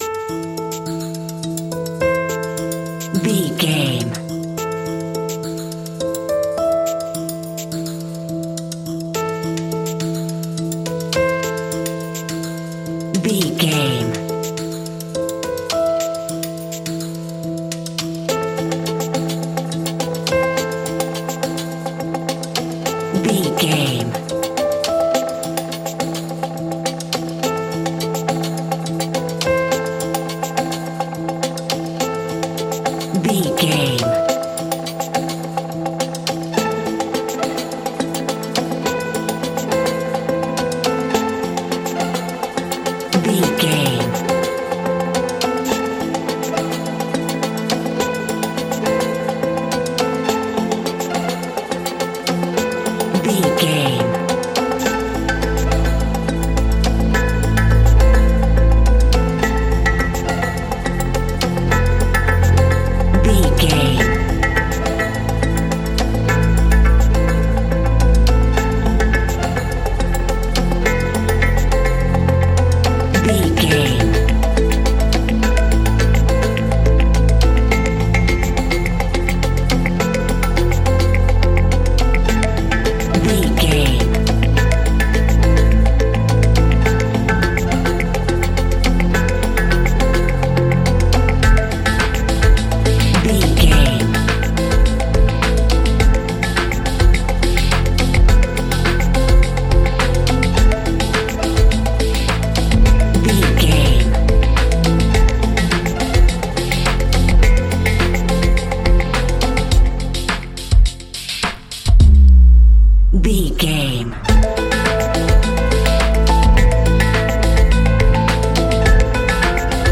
Ionian/Major
A♭
electronic
techno
trance
synths
synthwave
instrumentals